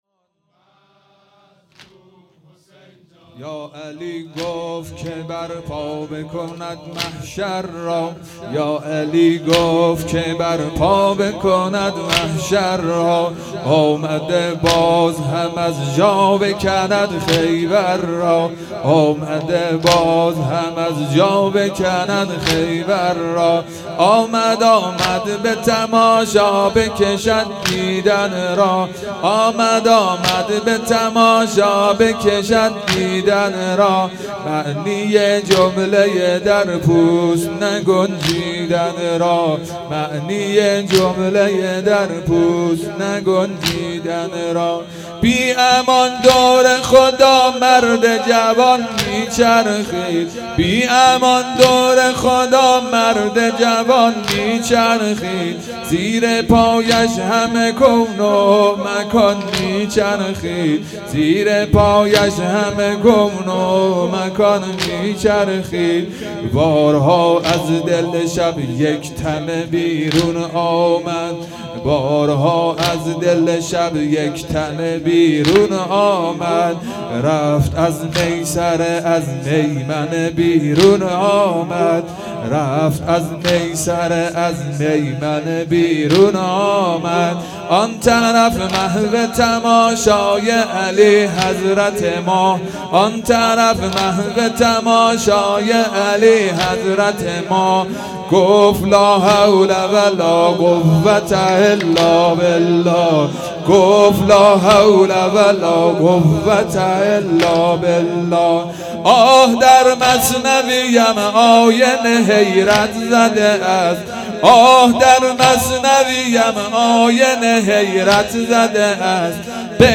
هيأت یاس علقمه سلام الله علیها
شب هشتم محرم الحرم 1441